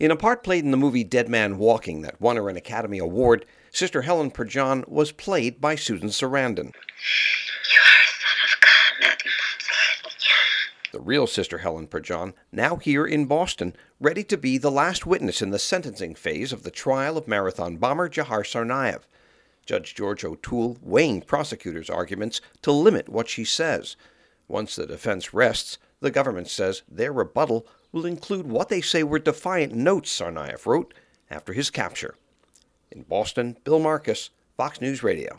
HAS MORE FROM BOSTON.